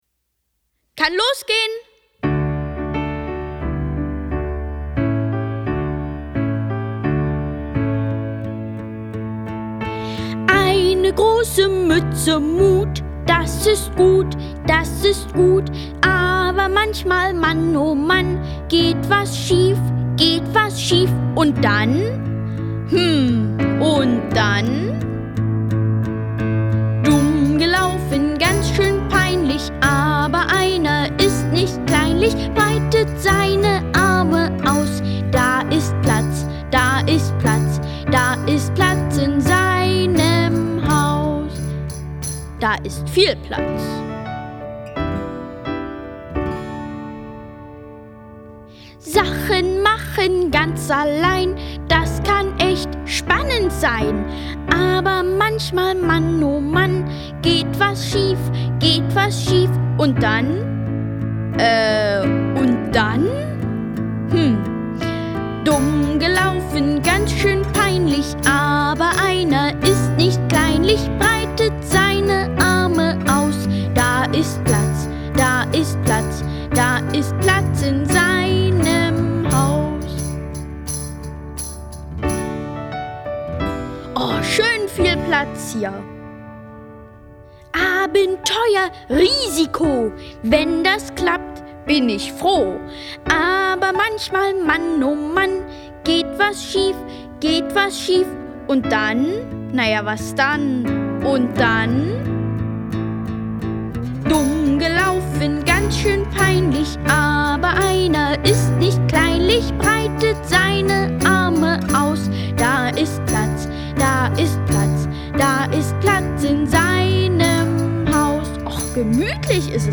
Jesus erzählt vom Reich Gottes. Die Hörbibel für Kinder. Gelesen von Katharina Thalbach und Ulrich Noethen
Ulrich Noethen, Katharina Thalbach (Sprecher)